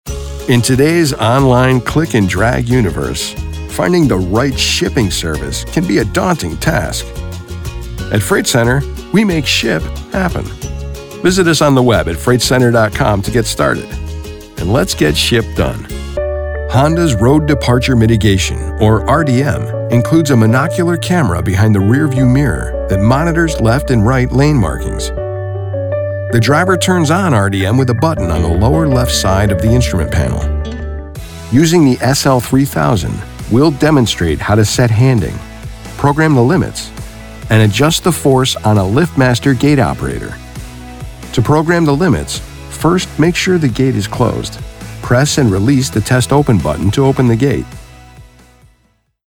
SOURCE-CONNECT Certified US MALE VOICOVER with HOME STUDIO
Medium Energy Voice-Over: Confident - Conversational - Comforting - Casual
• BOOTH: Whisper Room, acoustically-treated
explainer video
MIX6-explainer.mp3